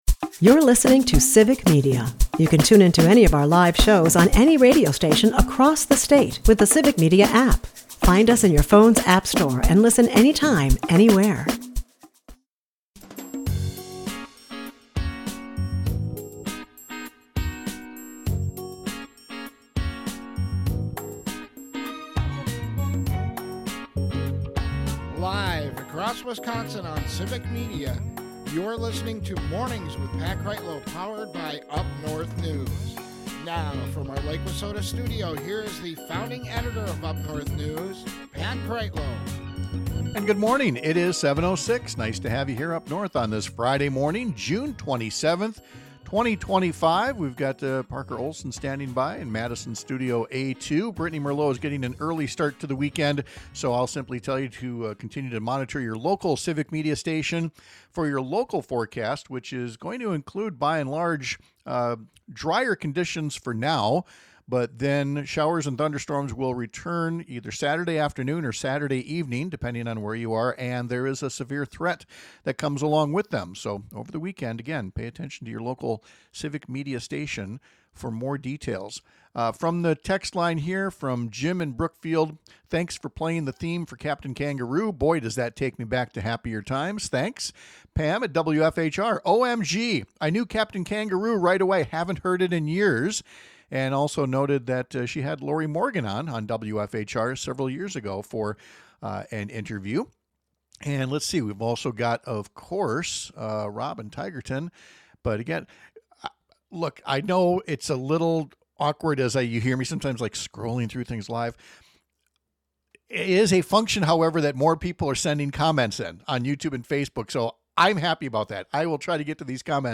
Former US Attorney Jim Santelle takes us through a flurry of US Supreme Court opinions being issued in the final days of their session—some of which reaffirm how extreme the right wing justices are taking American law.
and it airs on several stations across the Civic Media radio network, Monday through Friday from 6-9 am.